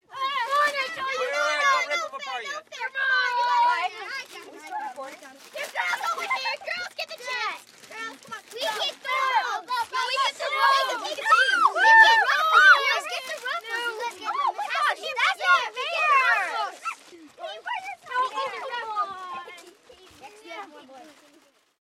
Звуки болтовни
Дети радостно общаются во дворе